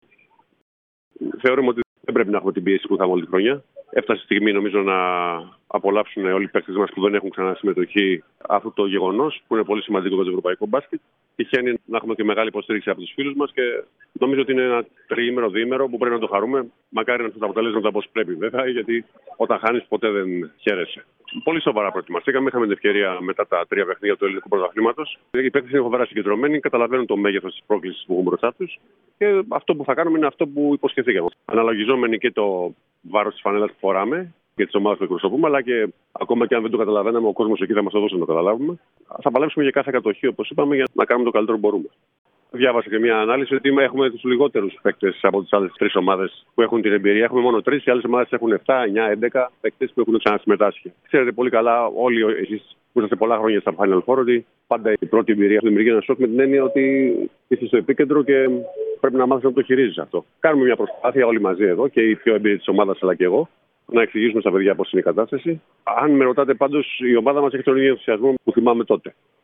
Ο προπονητής των κυπελλούχων Ελλάδας, μίλησε στους εκπροσώπους του Τύπου λίγο πριν την αναχώρηση για την σερβική πρωτεύουσα.